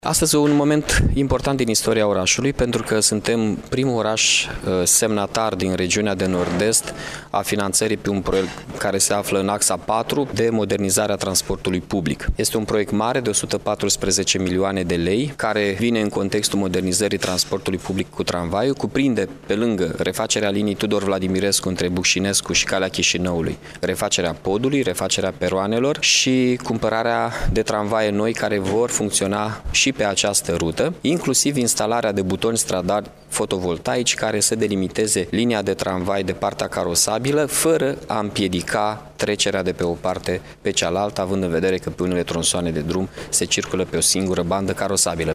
Primarul localităţii, Mihai Chirica, a oferit precizări privind investiţia care urmează a fi realizată.